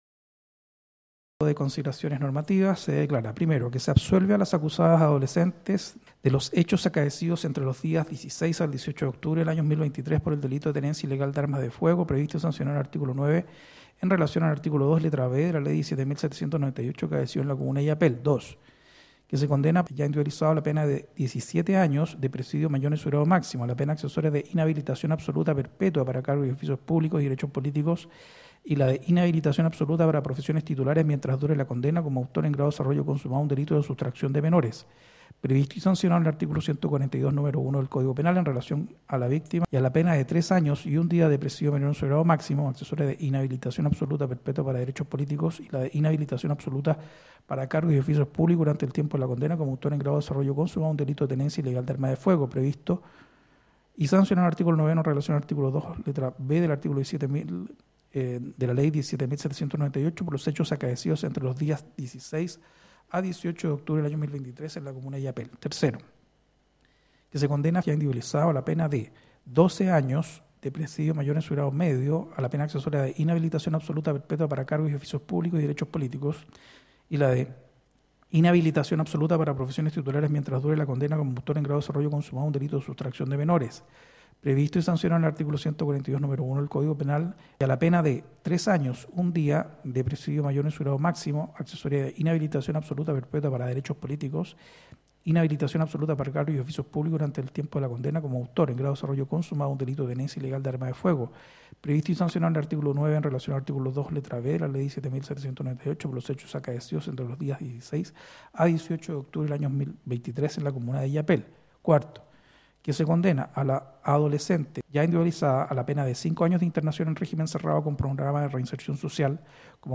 16-05-lectura-sentencia-sustraccion-de-menor-en-Illapel.mp3